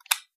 switch6.wav